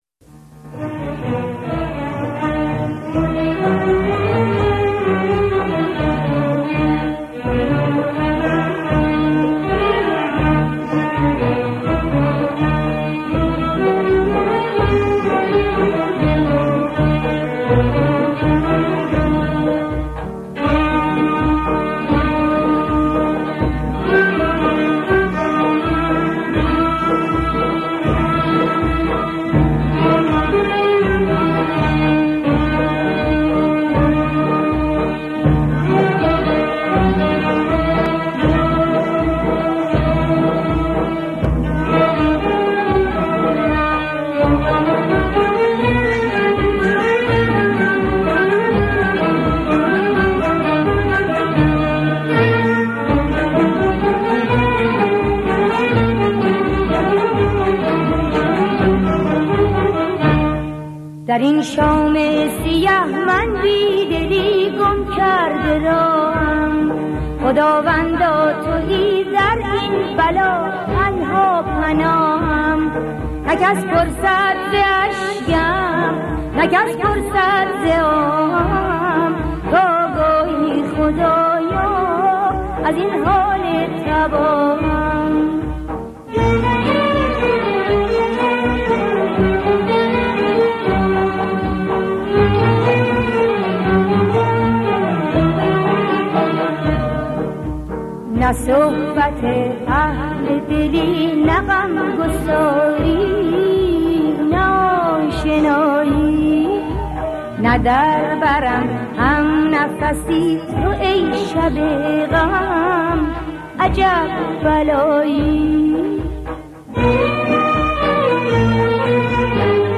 در مقام چهارگاه